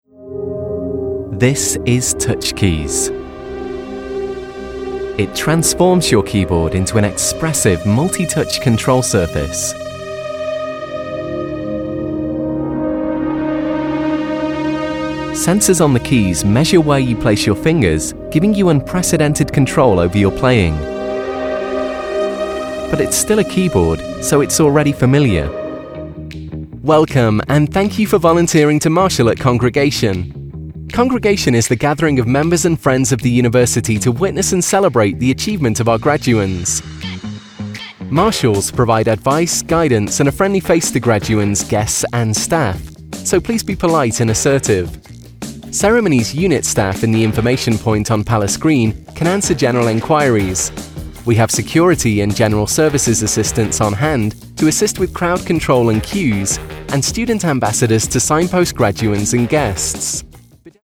Explainer Videos
I usually record with a warm and friendly tone of voice, or with more authoritative and formal tones.
Explainer Video Narration